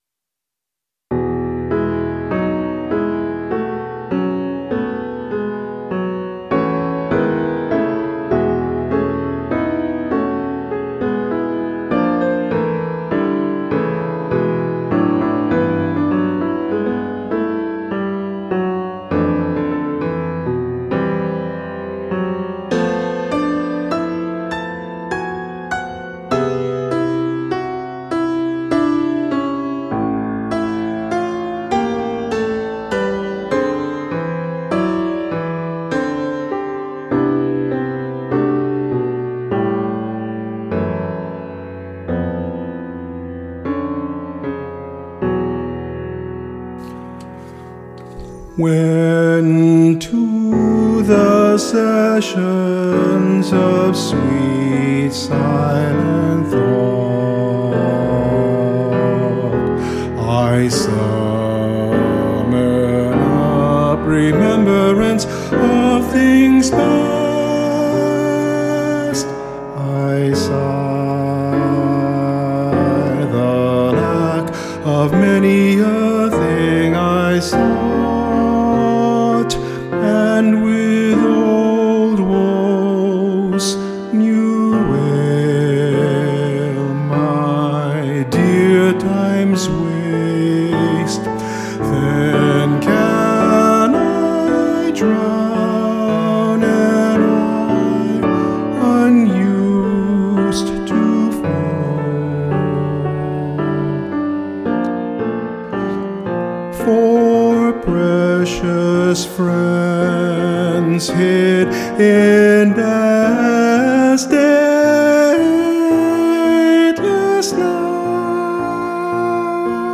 Voice | Downloadable    GO Download/Print